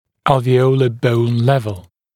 [ˌælvɪ’əulə bəun ‘levl][ˌэлви’оулэ боун ‘лэвл]уровень альвеолярной кости